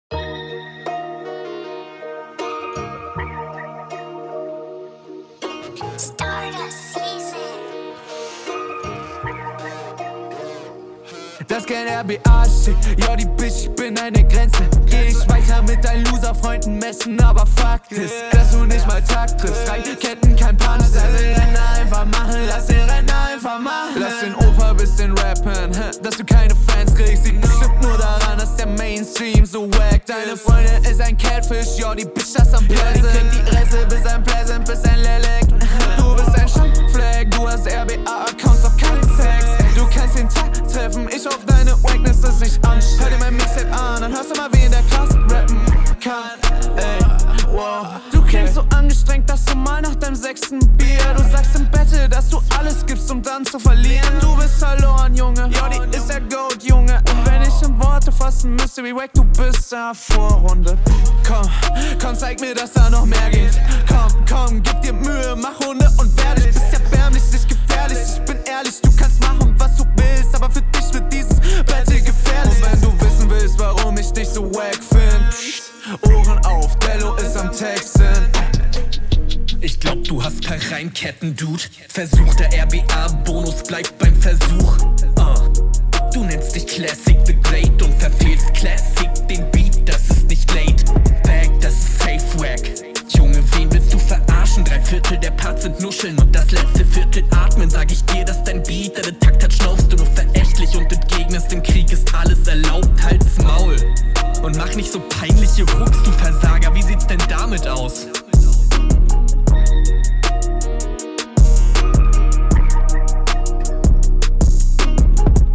Du klingst halt übel gelangweilt, ja klar ist der style aber Bockt für mich nicht. …